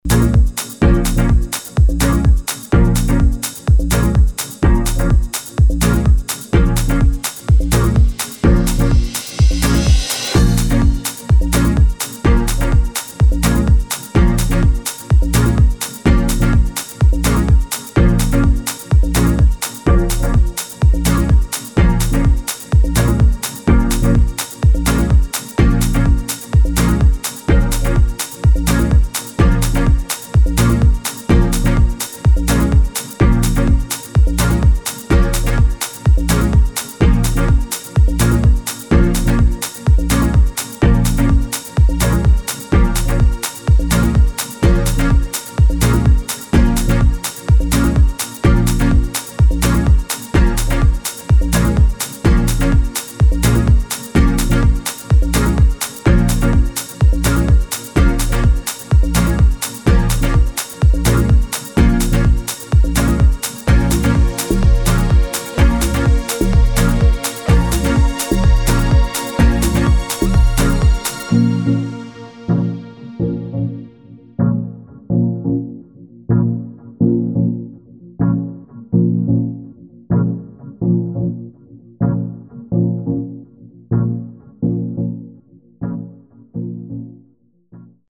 [ TECHNO / DEEP HOUSE ]